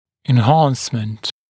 [ɪn’hɑːnsmənt] [en-][ин’ха:нсмэнт] [эн-]увеличение, повышение, прирост